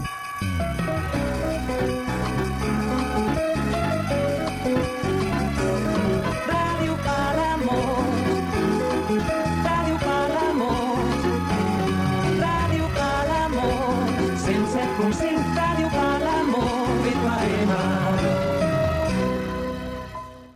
Indicatiu nadalenc de l'emissora